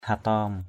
/ha-tɔ:m/